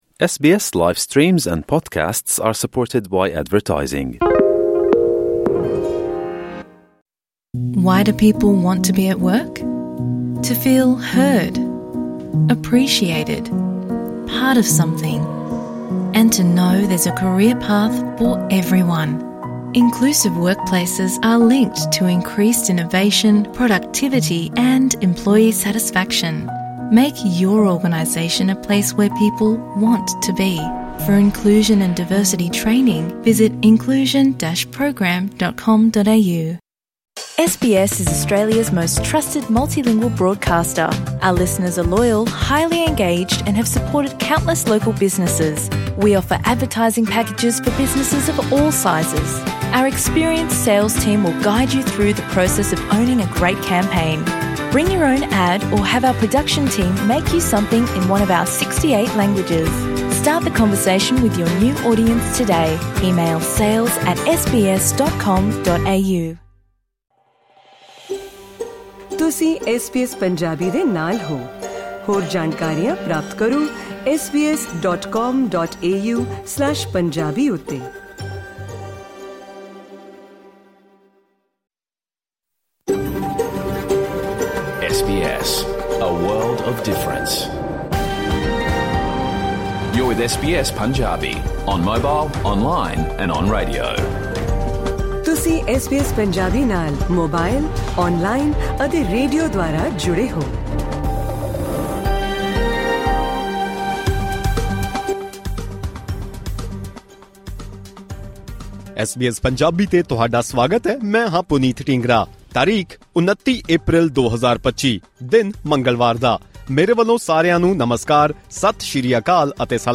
Listen to the SBS Punjabi 29th April 2025 full radio program | SBS Punjabi